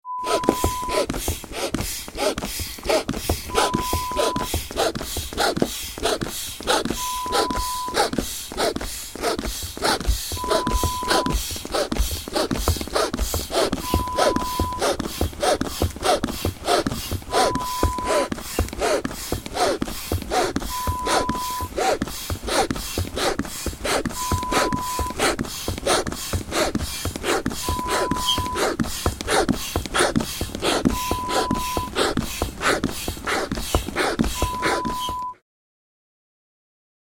Foot pump sound effect 2.wav
(0:35) FOOT PUMP SOUNDS: A bellow foot pump in use. Various inflating, pumping sounds delivered in CD quality WAV format (preview contains a security watermark tone).
PREVIEW = Lo-Fi mp3 with pink tone security watermark (beep).
Foot-Pump-sound-2--Lo-Fi-Preview-.mp3